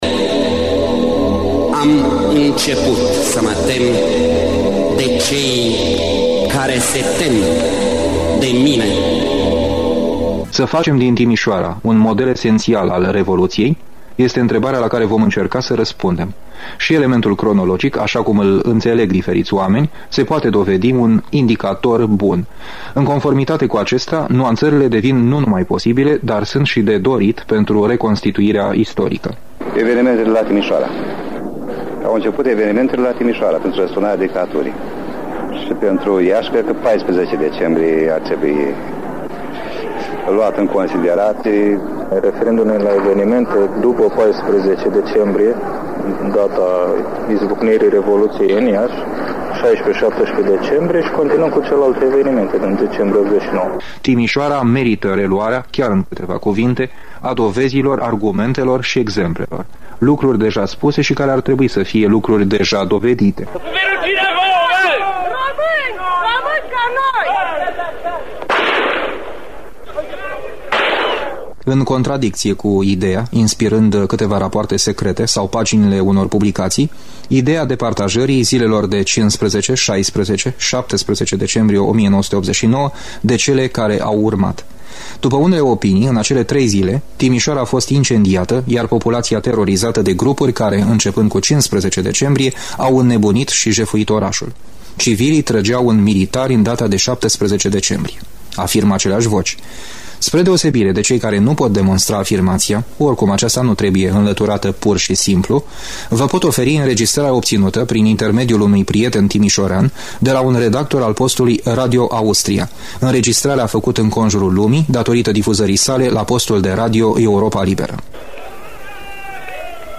documentar